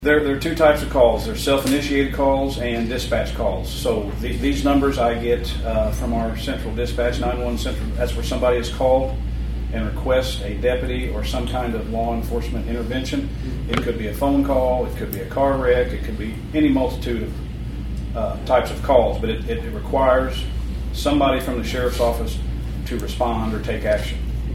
Sheriff Jeff Crites gave his report to the St. Francois County Commission on Tuesday, and said that they were likely to reach the 10,000 mark by the next commission meeting in July.
He explained to commissioners what would count as a call for service: